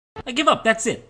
Play, download and share I give up that's it original sound button!!!!
smg4-sound-effects-i-give-up-that-s-it.mp3